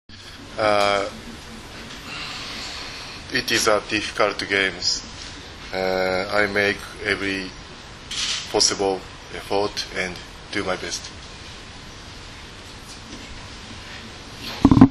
Danas je u Medija centru SPC „Vojvodina“ u Novom Sadu održana konferencija za novinare, kojoj su prisustvovali kapiteni i treneri Kube, Srbije, Rusije i Japana
IZJAVA